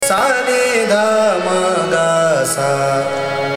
ThaatBhairavi
AvarohaS’ n d m g S
Malkauns (Avaroha)